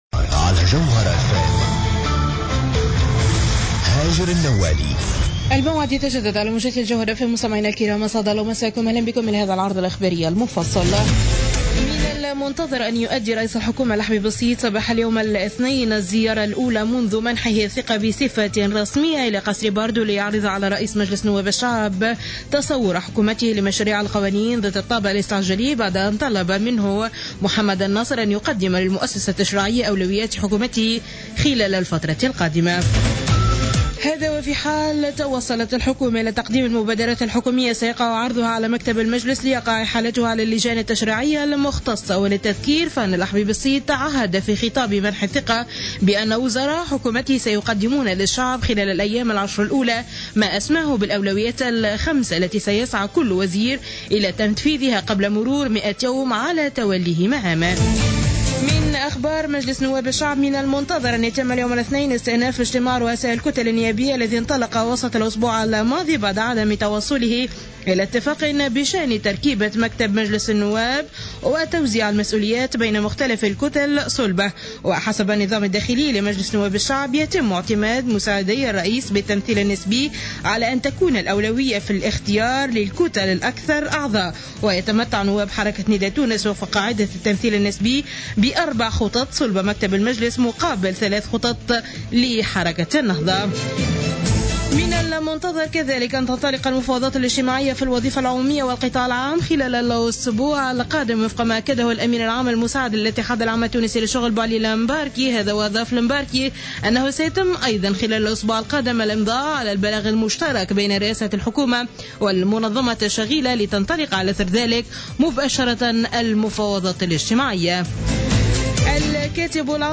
نشرة أخبار منتصف الليل ليوم الإثنين 15 فيفري 2015